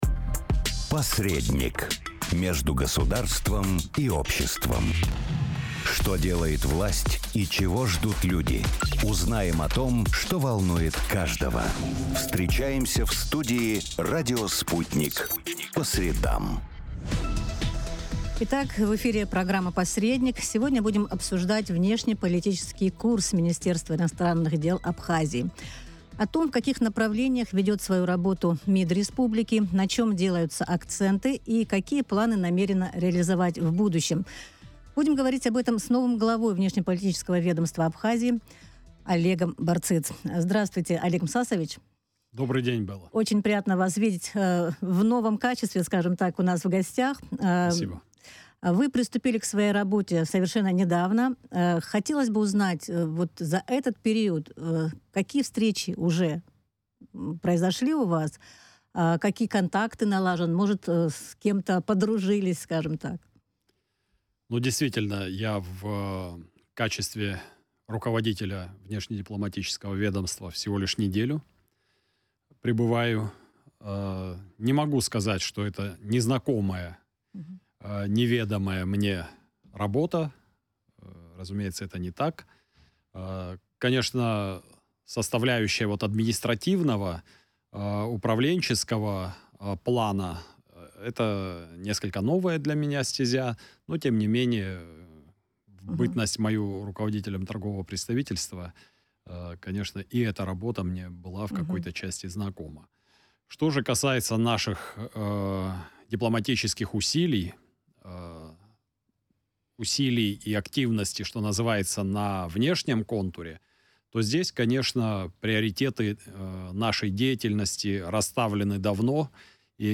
18 апреля президент Абхазии Бадра Гунба министром иностранных дел назначил Олега Барциц. В эфире радио Sputnik глава внешнеполитического ведомства рассказал о приоритетных задачах, стоящих перед министерством.